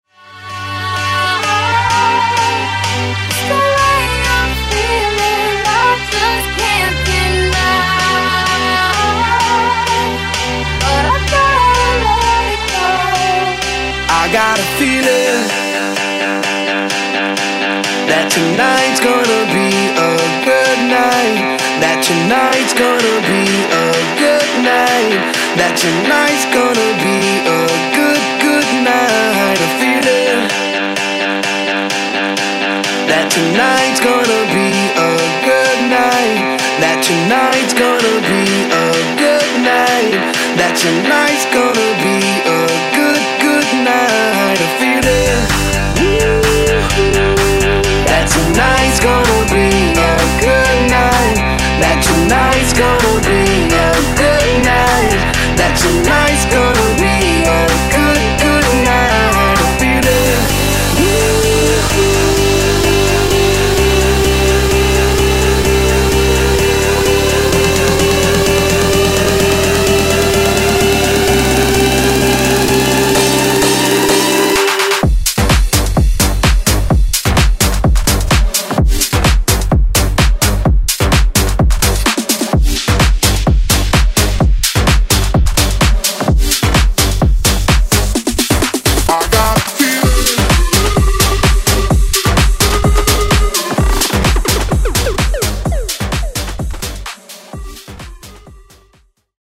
Blend Mixshow Edit)Date Added